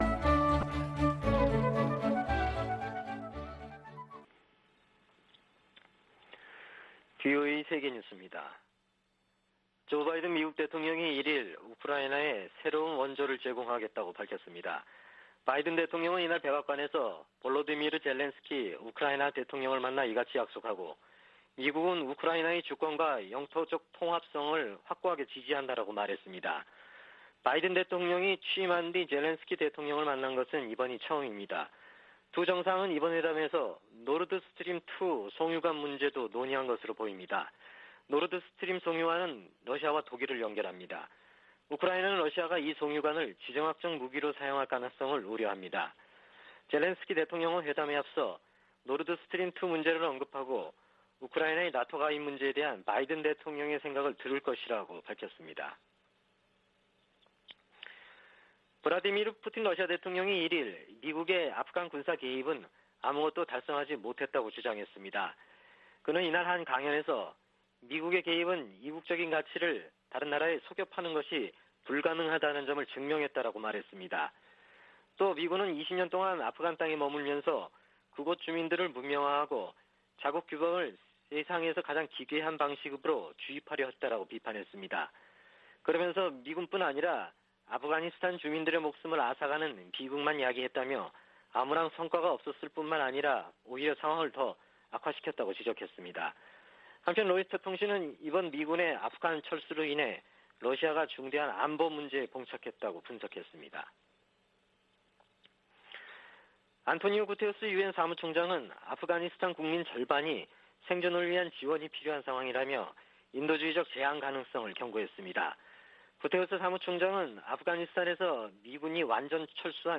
VOA 한국어 아침 뉴스 프로그램 '워싱턴 뉴스 광장' 2021년 9월 2일 방송입니다. 미 연방 관보에 8월 31일 현재 북한 여행금지 조치를 연장한다는 내용이 게시되지 않았습니다. 백악관은 북한 핵 문제와 관련해 대화의 문이 열려 있으며 조건 없는 만남 제안이 여전히 유효하다고 밝혔습니다. 북한 핵 물질의 핵심은 고농축 우라늄이며 최근 영변에서 플루토늄 생산을 위한 원자로를 가동한 것은 대미 압박 성격이라고 국제원자력기구(IAEA) 전 사무차장이 분석했습니다.